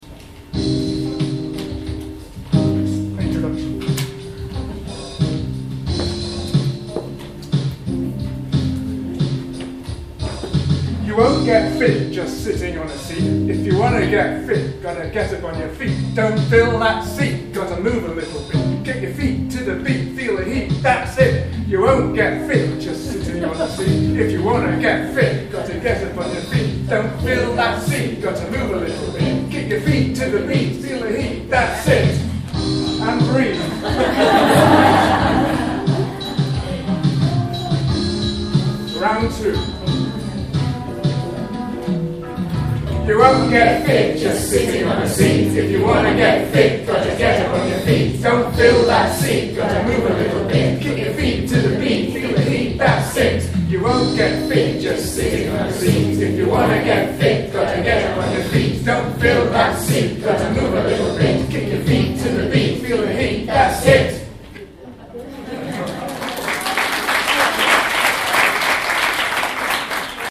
TESOL Spain